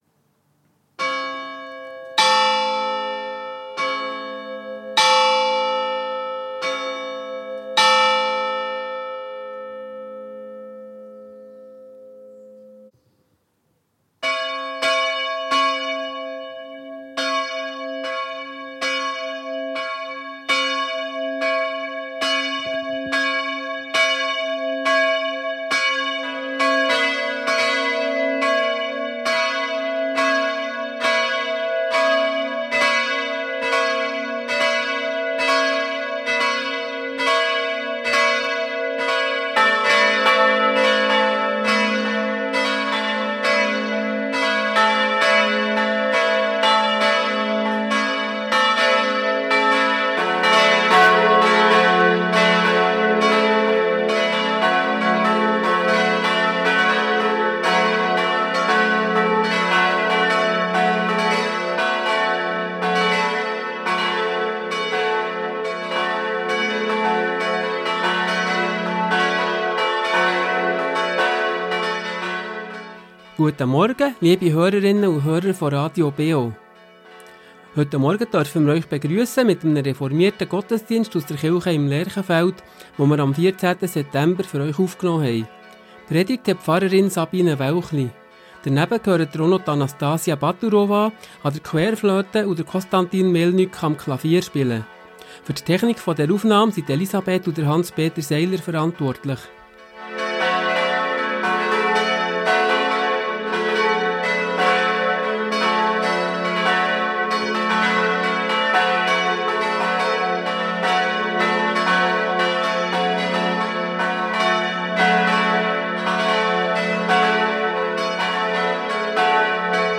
Reformierte Kirche Thun Lerchenfeld ~ Gottesdienst auf Radio BeO Podcast